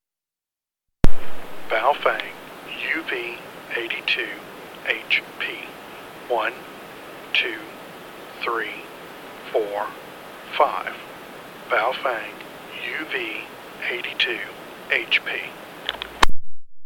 This radio seems to sputter a little bit right as you key up, but it clears up pretty quickly.
baofeng-uv82hp.mp3